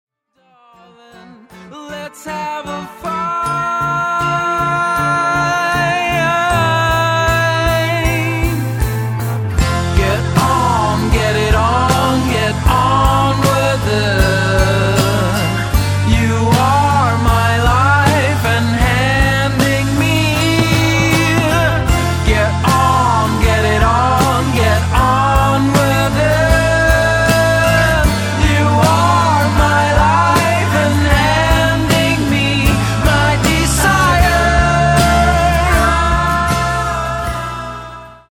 Guitar Pop/Swedish